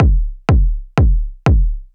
First up is through my Hot Tubes. It doesn’t sound bad, sounds good, but it’s not the best I’ve heard either. would definitly use it in a track though.
It have been eq’ed and compressed when I made it, but had to eq it a bit more after the overdrive to get rid of too much low end and mids that got accentuated.
Now that I hear it uploadet the overdriven ones could have a bit more bottom maybe.